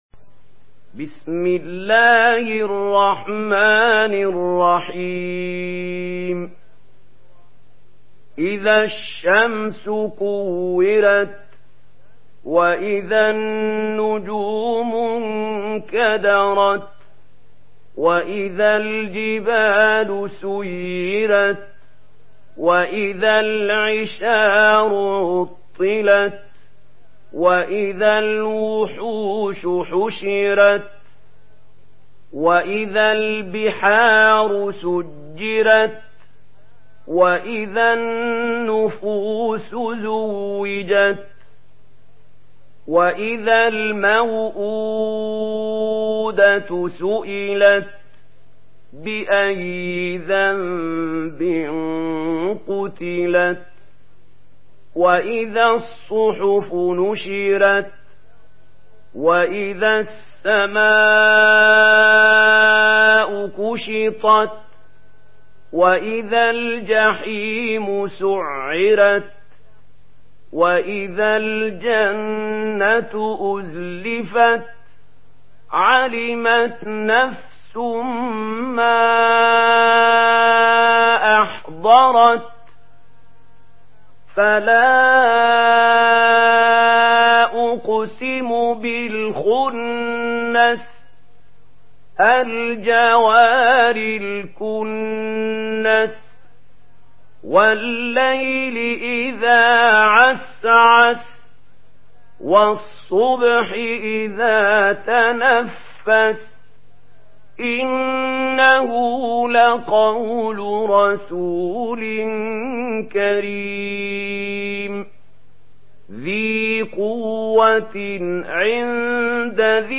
Une récitation touchante et belle des versets coraniques par la narration Warsh An Nafi.